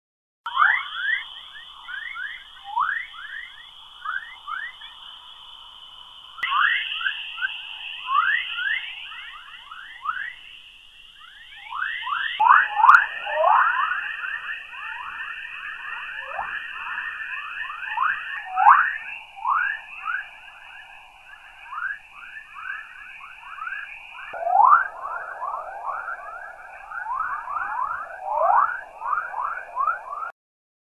Sounds From Space
Radio waves from Earth's magnetosphere 00:30
Radio_Waves_Earth_Magnetosphere.wav